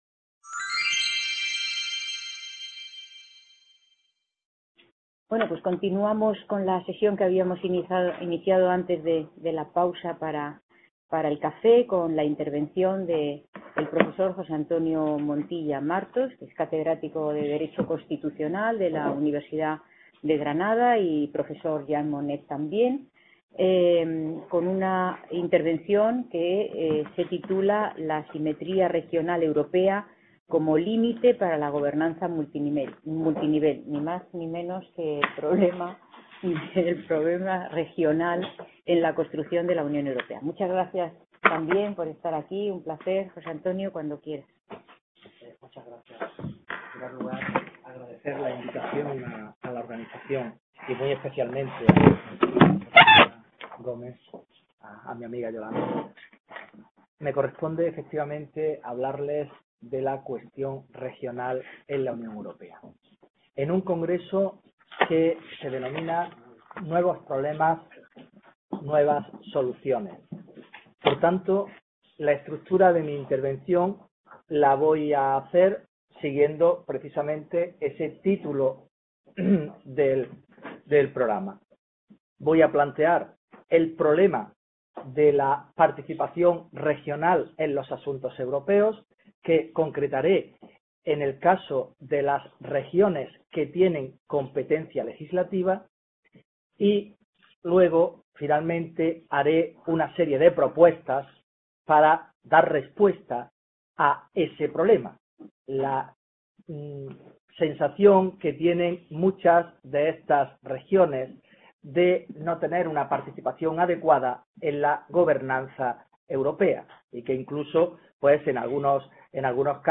Description C.A. Bruselas - IV Congreso Internacional sobre Unión Europea: nuevos problemas, nuevas soluciones.